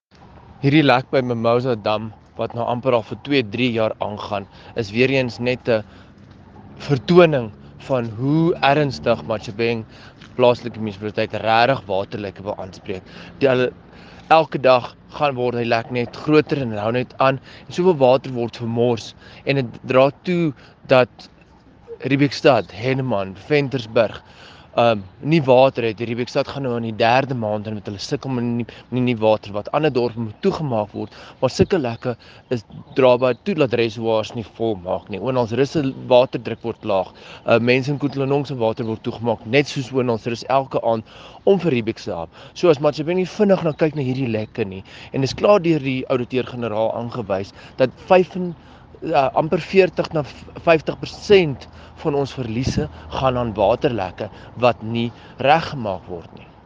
Issued by Dr Igor Scheurkogel – DA Councillor Matjhabeng Local Municipality
Afrikaans soundbites by Dr Igor Scheurkogel.